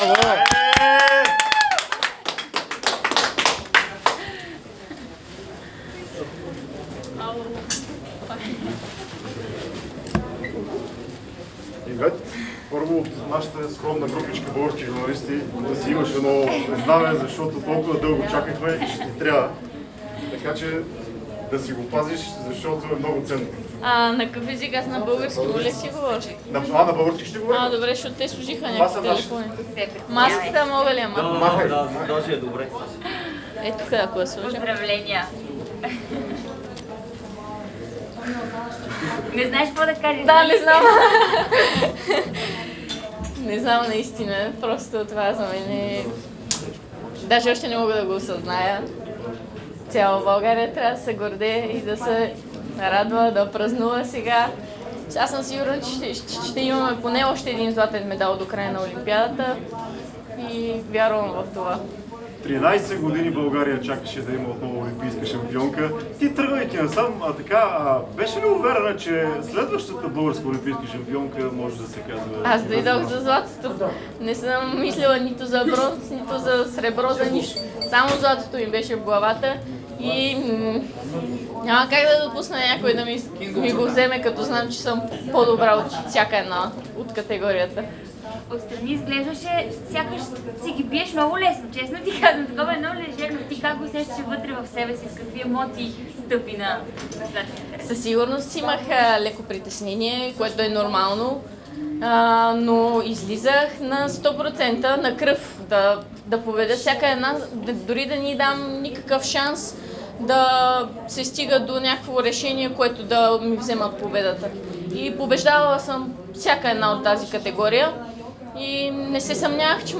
Чуйте още от Ивет Горанова от микс зоната в Токио в прикаченото аудио!